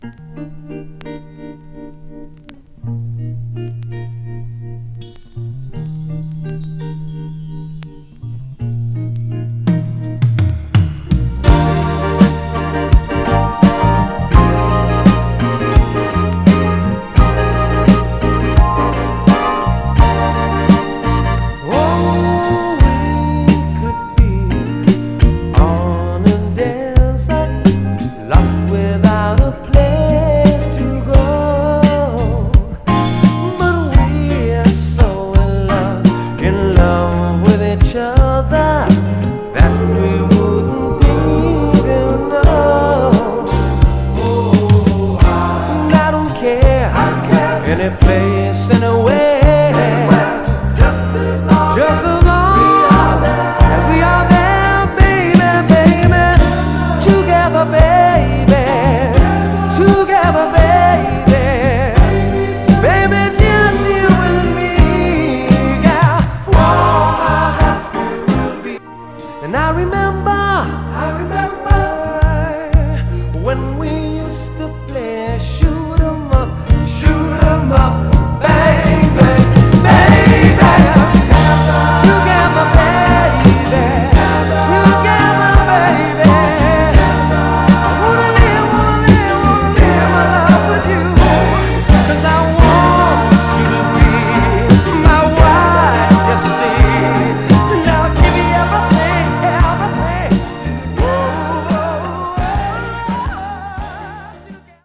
盤はエッジに一部目立つスレ箇所ありますが、全体的にはグロスがありプレイ概ね良好です。
※試聴音源は実際にお送りする商品から録音したものです※